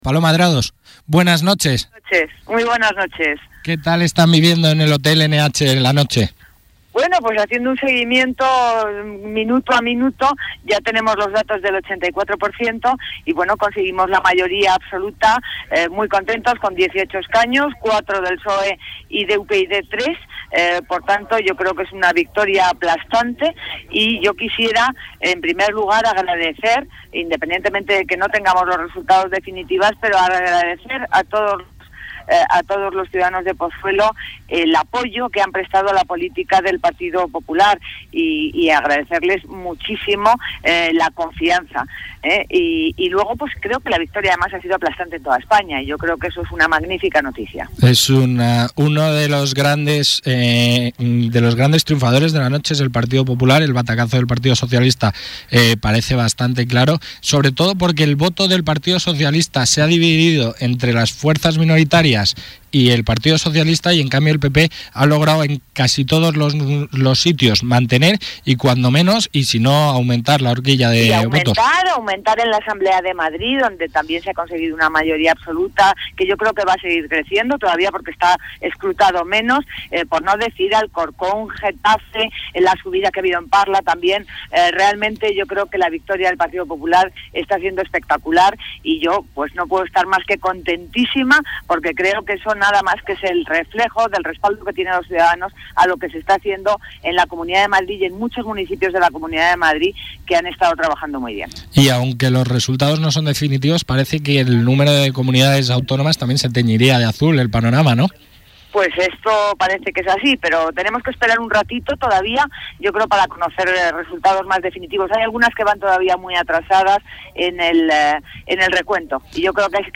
Paloma Adrados, que será la nueva alcaldesa de Pozuelo de Alarcón, declaró ayer en directo en el programa Especial Elecciones Madrid de Pozuelo Radio">Pozuelo Radio: "La noche la hemos pasado mirando los datos minuto a minuto, conseguimos la mayoría absoluta y estamos muy contentos".
reproducir_audio    Escuche las declaraciones de Adrados tras vencer en las elecciones municipales
entrevista_adrados_22demayo.mp3